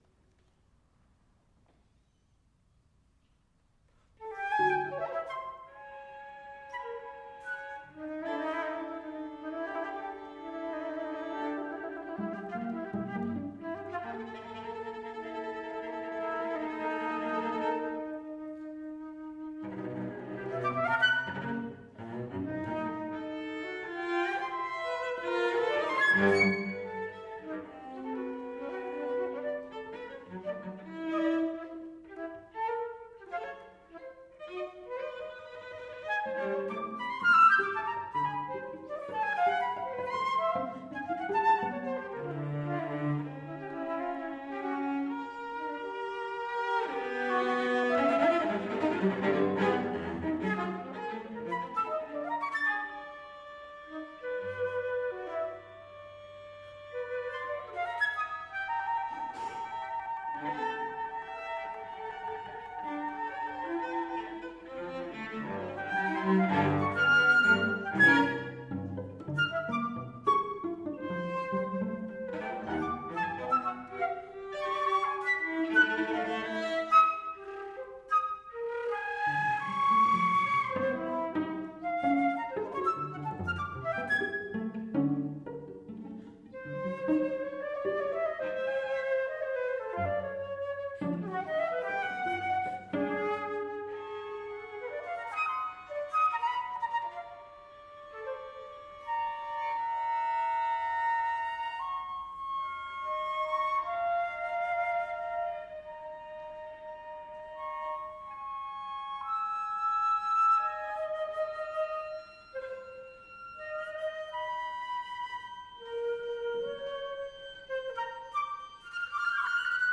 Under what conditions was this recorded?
Live concert recording